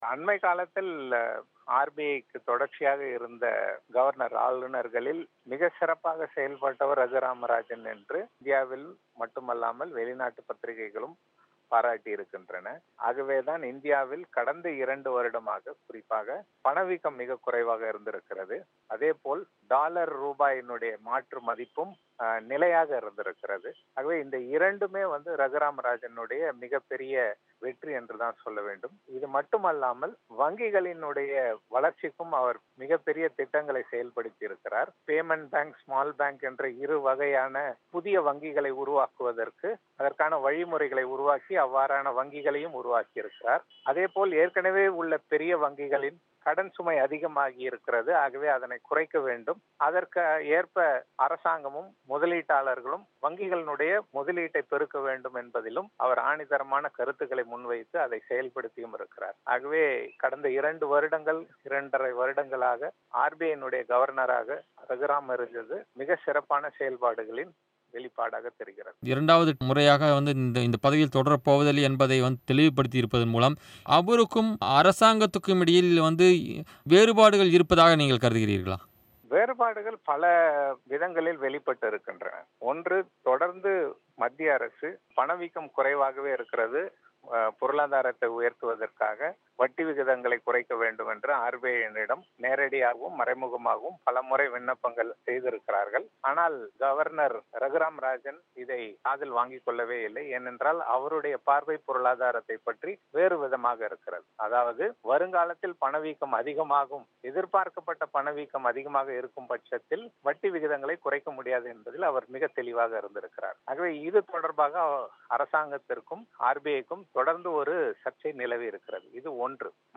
அவரது பேட்டியின் ஒலி வடிவத்தை நேயர்கள் இங்கு கேட்கலாம்.